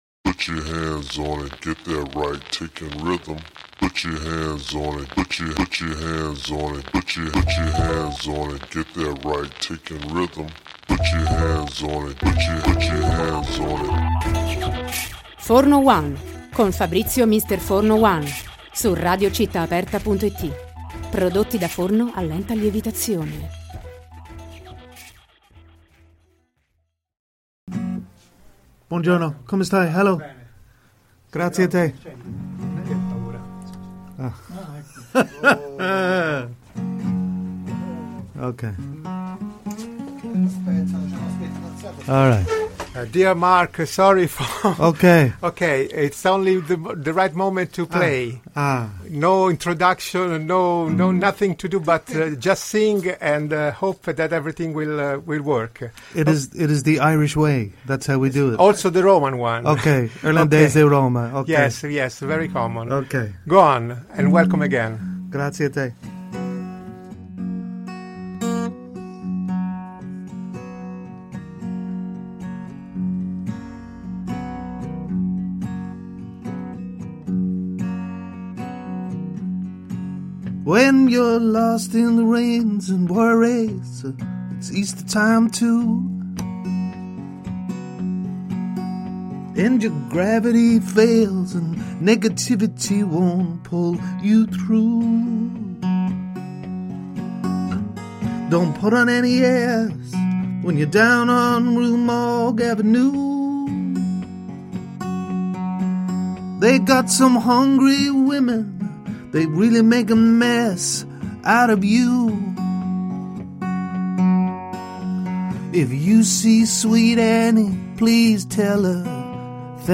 protagonista di un mini live negli studi della radio